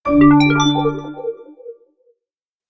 06321 message ding - elegant
ding elegant info message multimedia notification pleasant sfx sound effect free sound royalty free Sound Effects